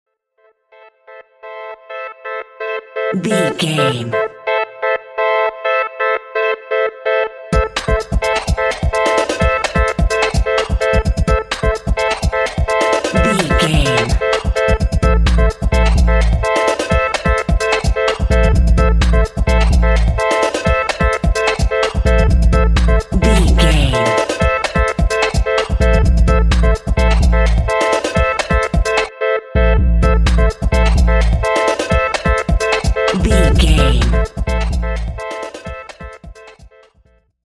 Groovy Dub Step.
Aeolian/Minor
electro
synths
synth lead
synth bass
synth drums